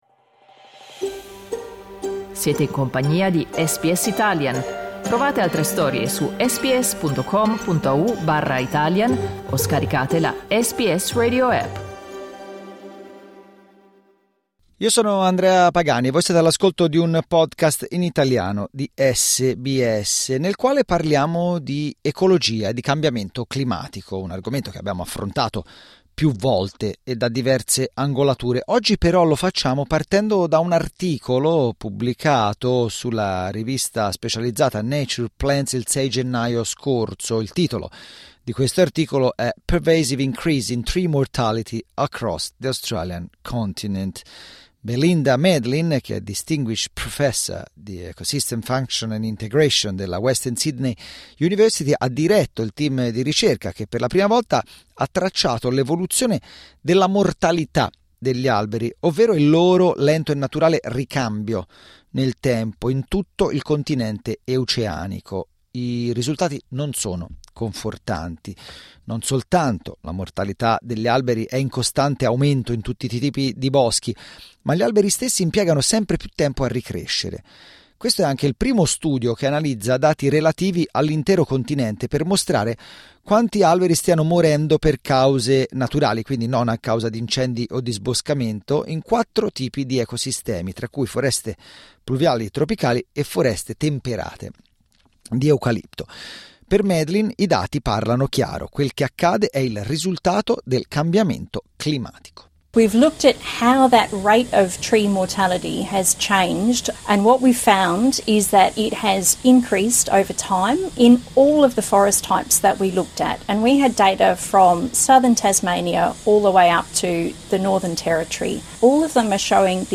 Secondo una recente ricerca, gli alberi in svariati boschi australiani stanno morendo ad un ritmo maggiore di quello con cui nuovi alberi nascono: un altro effetto del cambiamento climatico. Ne abbiamo parlato con alcuni esperti.